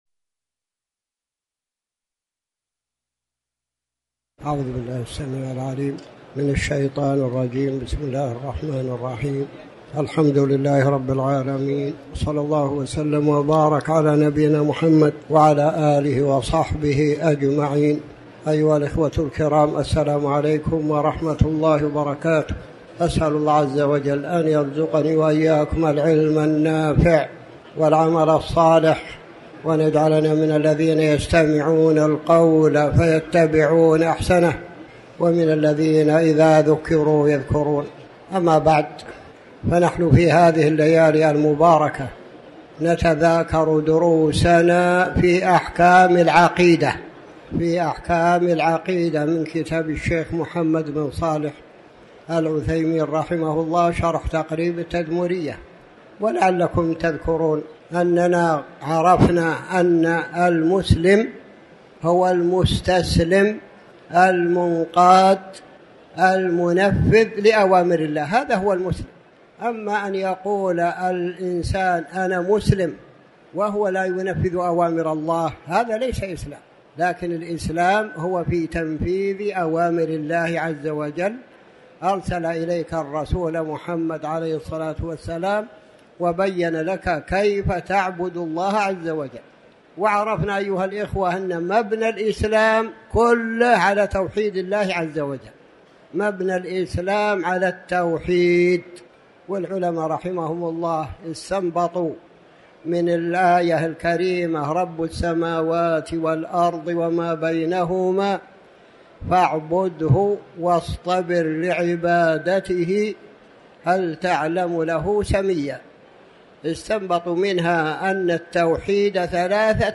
تاريخ النشر ٢٤ ربيع الثاني ١٤٤٠ هـ المكان: المسجد الحرام الشيخ